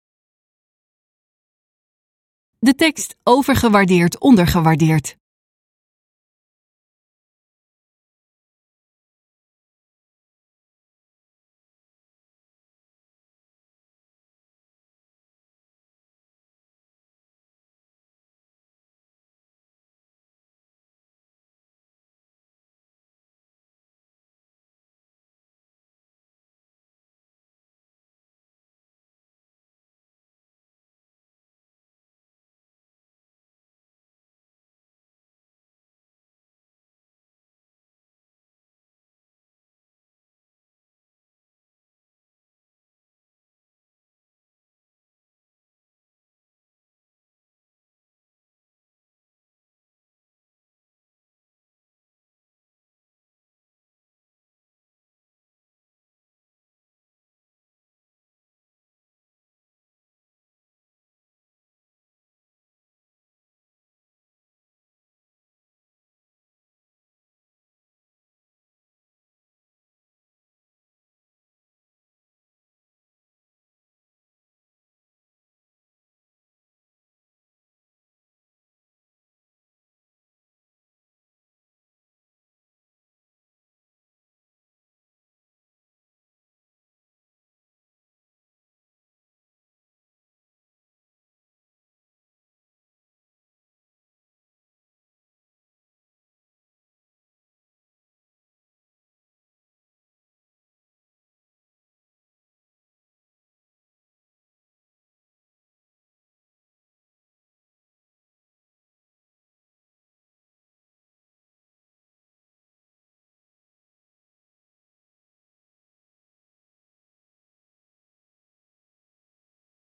In de videorubriek van Overheid van Nu interviewen we dwarsdenkers in en rond het openbaar bestuur aan de hand van actuele begrippen.